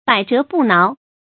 注音：ㄅㄞˇ ㄓㄜˊ ㄅㄨˋ ㄣㄠˊ
百折不撓的讀法